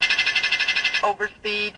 warnOverspeed.ogg